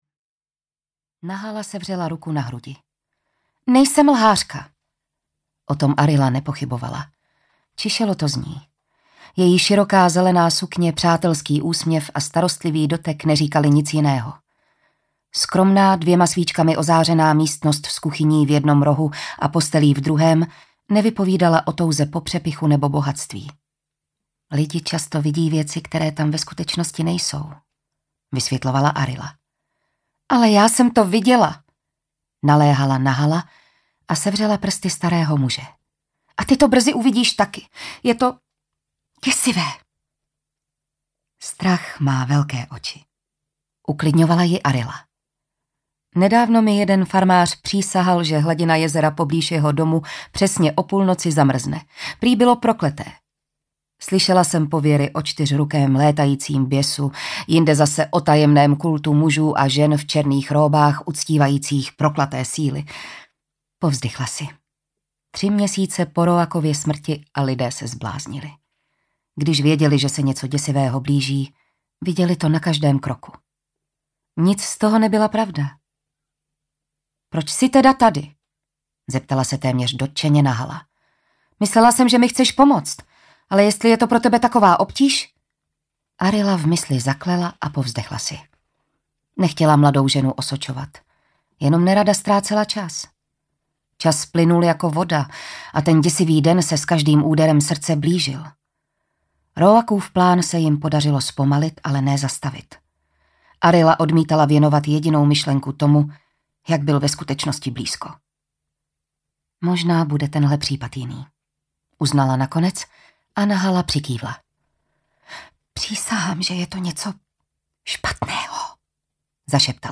Arila: Pád temnoty audiokniha
Ukázka z knihy
arila-pad-temnoty-audiokniha